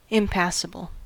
Ääntäminen
Ääntäminen US UK : IPA : /ɪmˈpasɪbəl/ Haettu sana löytyi näillä lähdekielillä: englanti Käännöksiä ei löytynyt valitulle kohdekielelle.